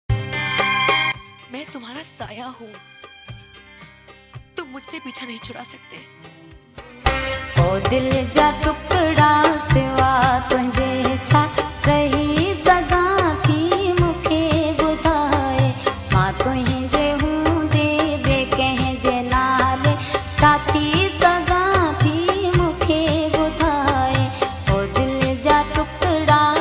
Old Sindhi Hits Song Broken Hearts sad Song